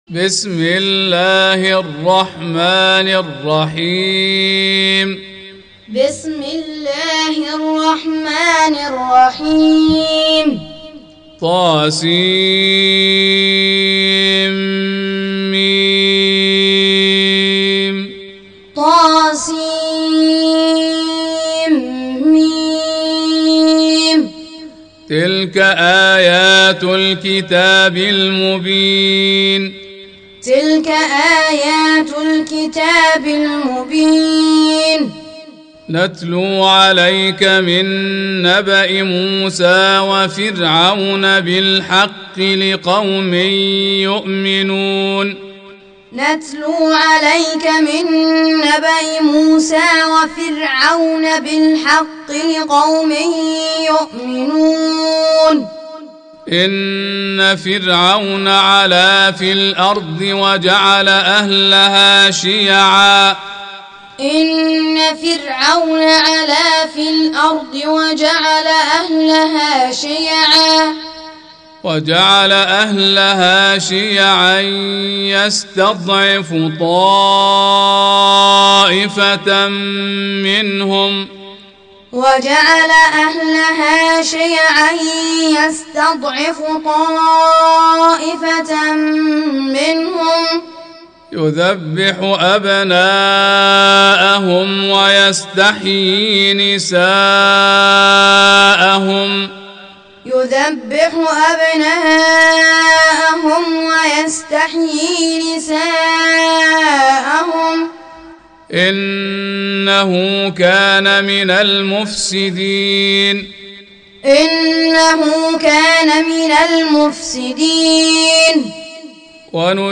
28. Surah Al-Qasas سورة القصص Audio Quran Taaleem Tutorial Recitation Teaching Qur'an One to One